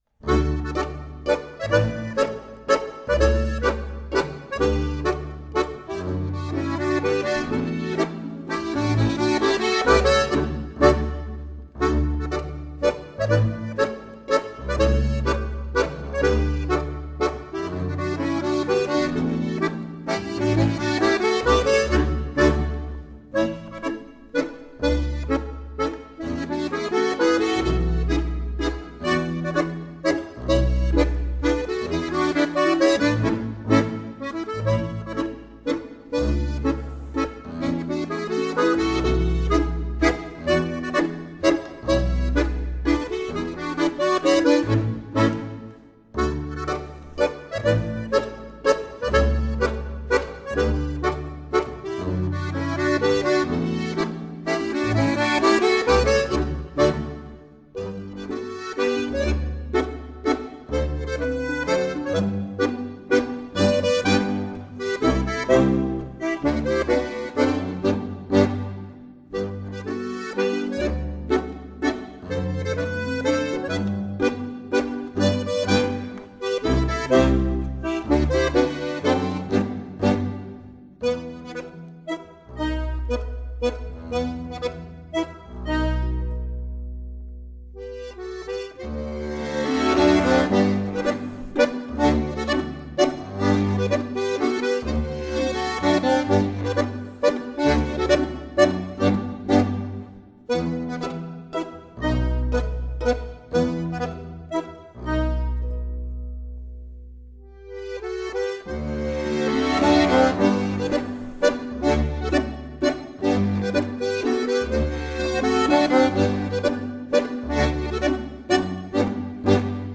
15_Der_schoene_Schneider_Mazurka.mp3